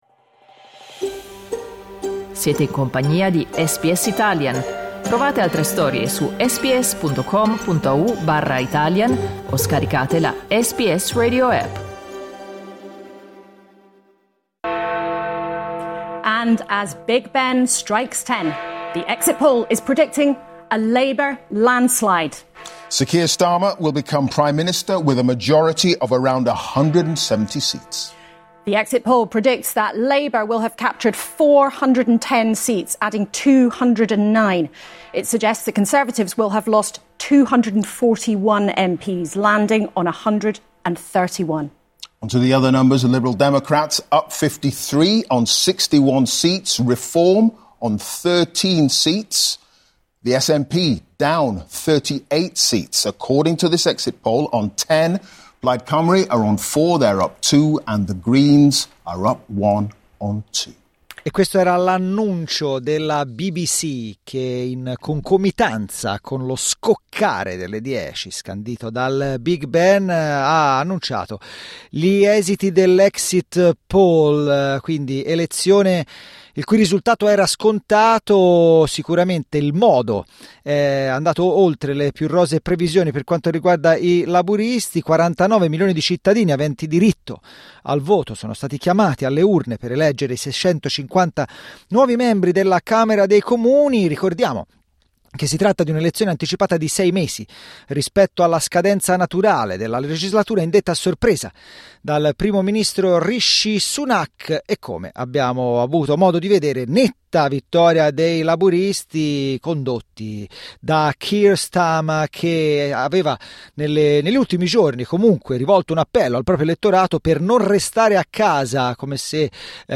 Ascolta l’analisi degli exit poll cliccando il tasto “play” in alto